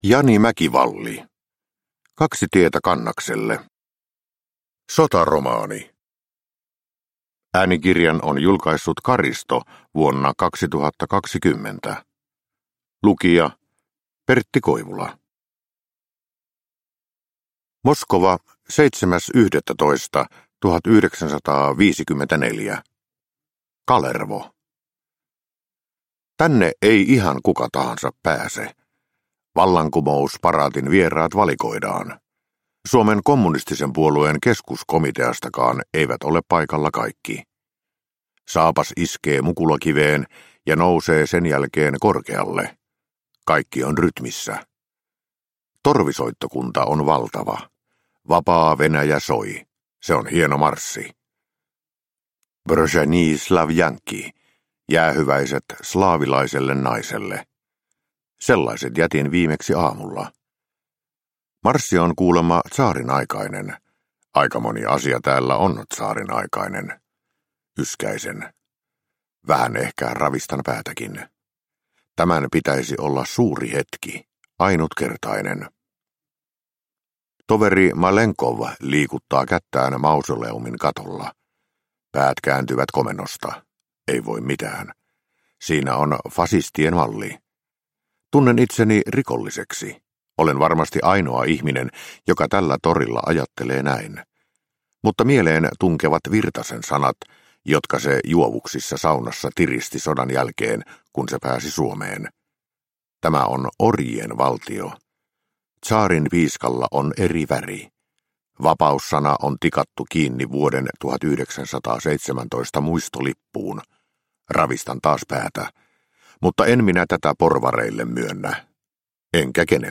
Kaksi tietä Kannakselle – Ljudbok – Laddas ner
Uppläsare: Pertti Koivula